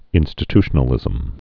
(ĭnstĭ-tshə-nə-lĭzəm, -ty-)